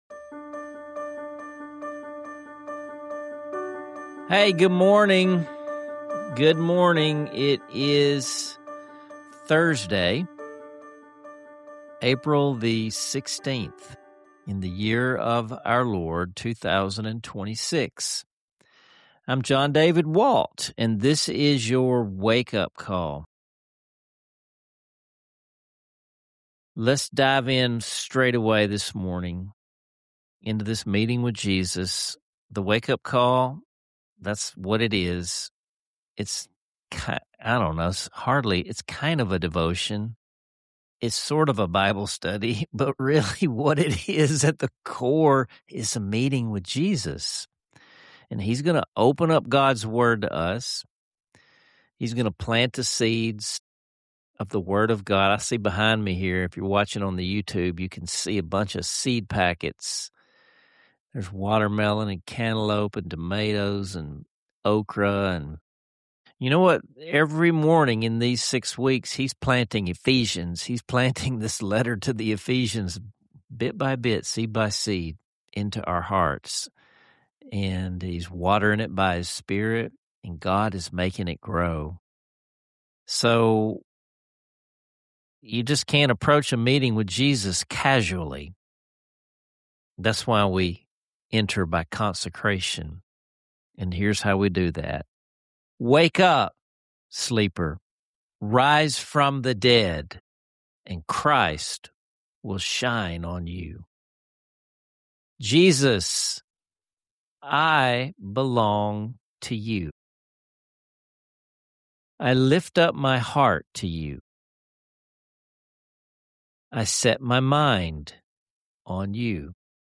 An uplifting rendition of “Blessed Assurance,” reminding us all that our stories are rooted in divine grace.